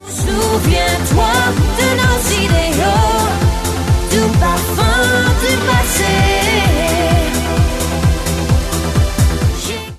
belgijska wokalistka